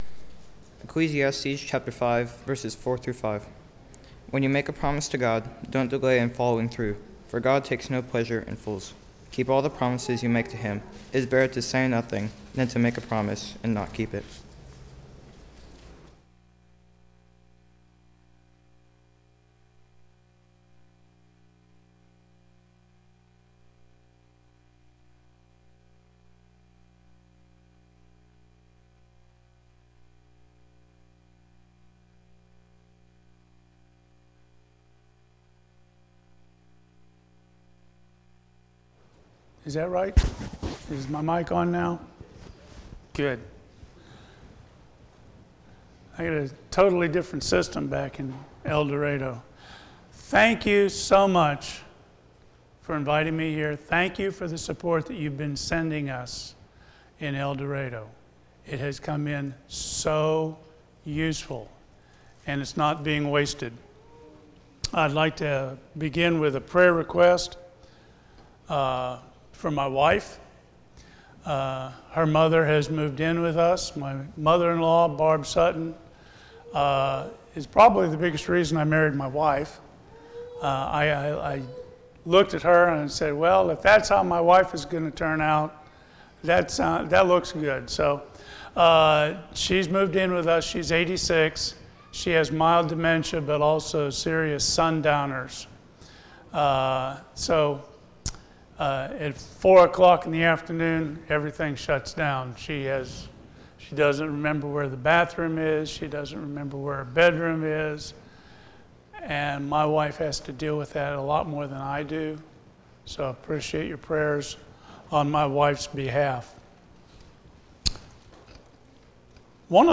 Service Type: Sunday AM Topics: church , responsibility